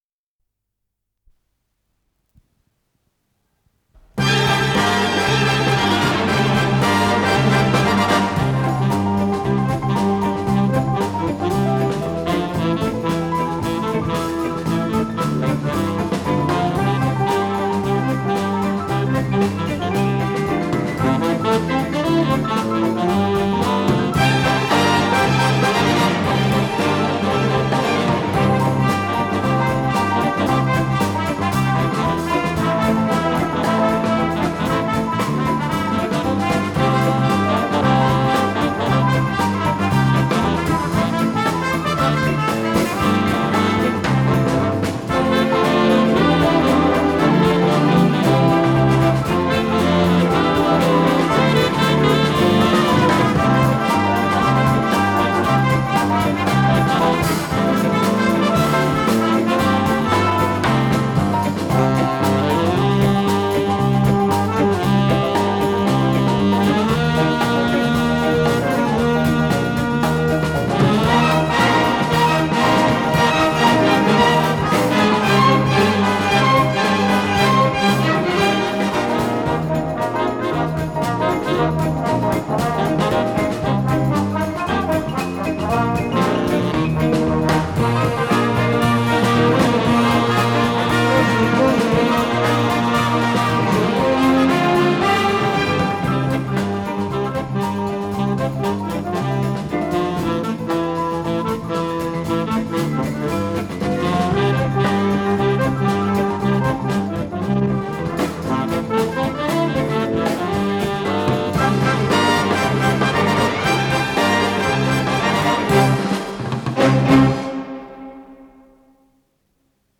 с профессиональной магнитной ленты
ПодзаголовокПьеса для эстрадного оркестра, до мажор
ВариантДубль моно